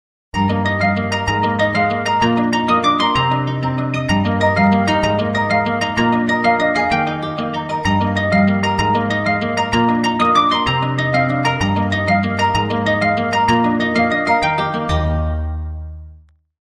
добрые